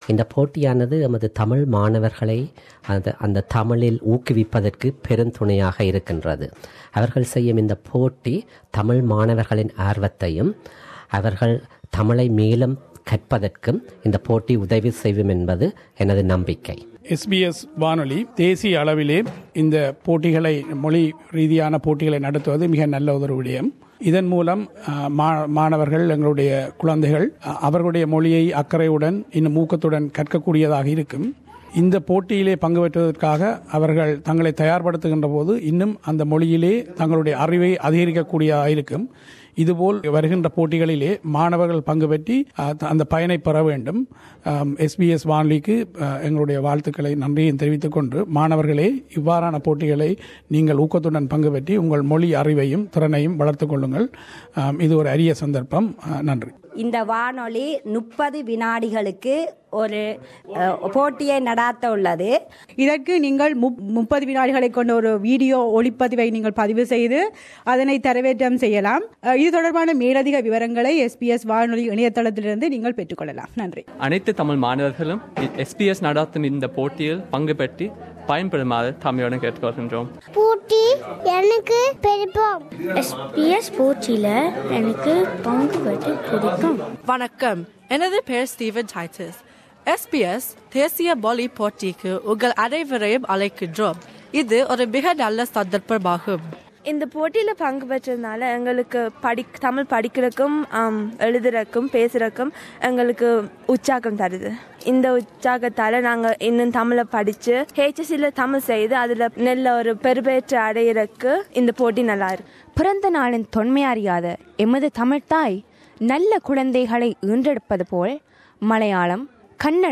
Voxpop - SBS National Languages Competition - enter now